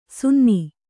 ♪ sunni